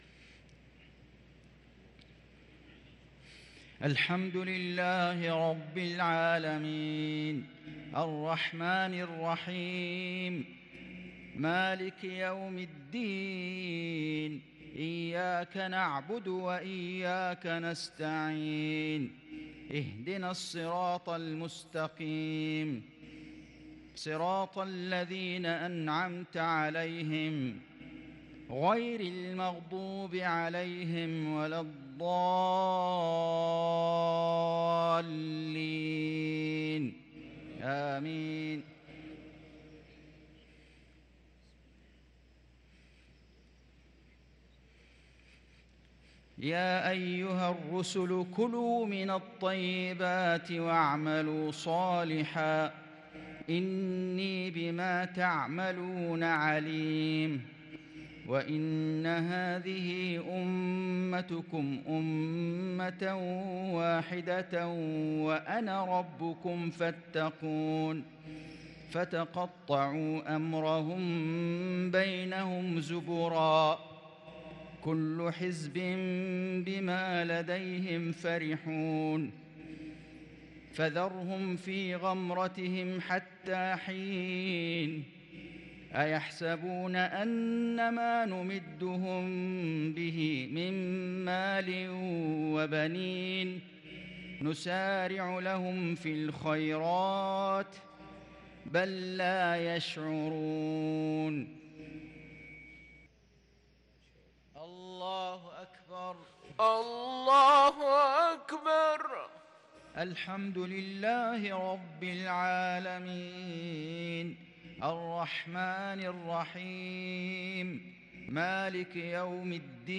صلاة المغرب للقارئ فيصل غزاوي 23 محرم 1444 هـ
تِلَاوَات الْحَرَمَيْن .